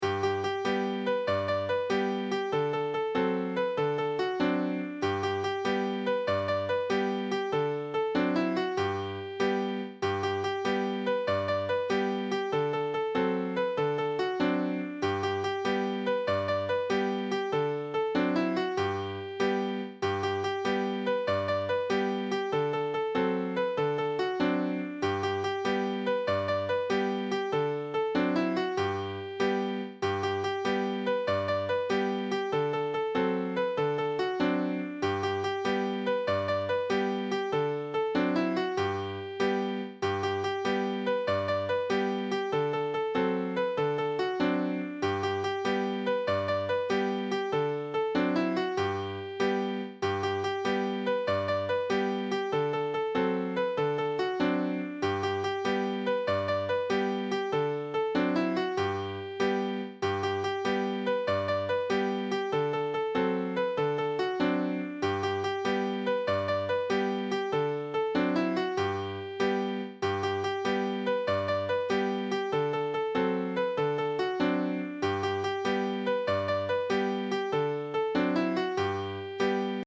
To the tuneof "Here we go round the mulberry bush"